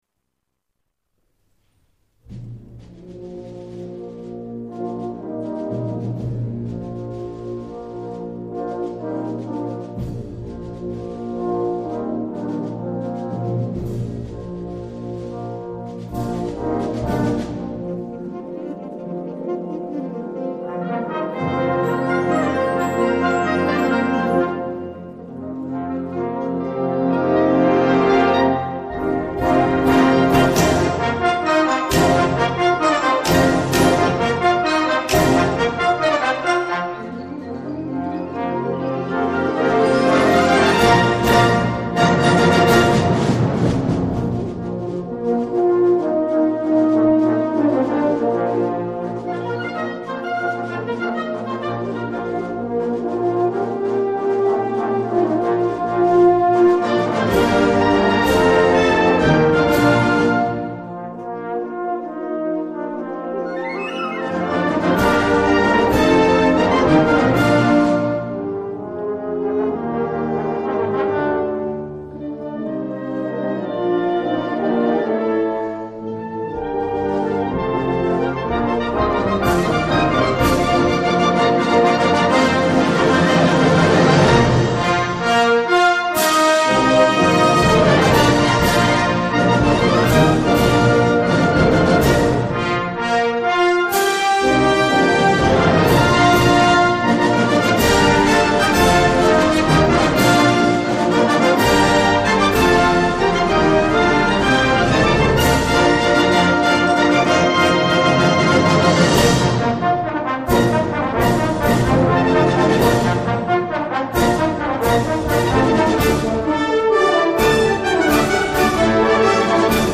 Fantasía -fragmento- (Obra sinfónica